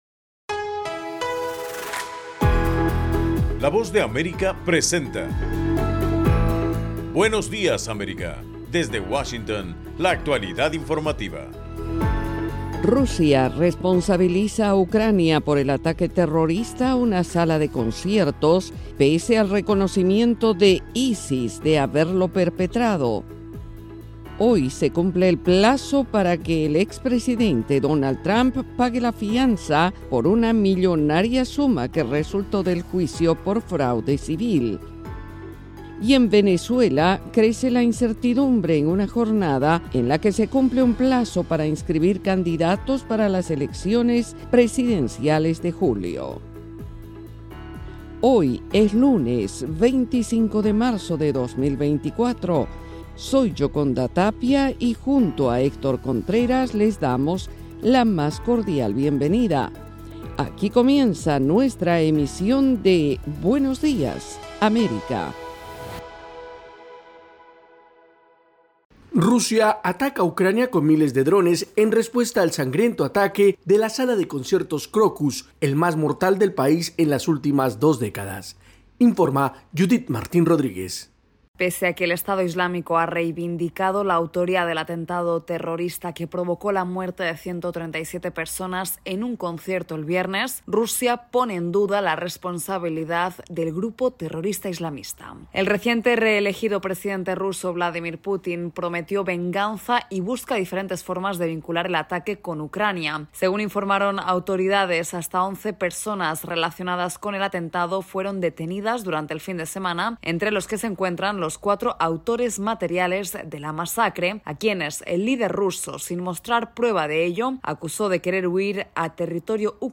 En el programa de hoy, 25 de marzo, Rusia responsabiliza a Ucrania por el ataque terrorista a una sala de conciertos pese al reconocimiento de ISIS de haberlo perpetrado. Esta y otras noticias de Estados Unidos y América Latina en Buenos Días América, un programa de la Voz de América.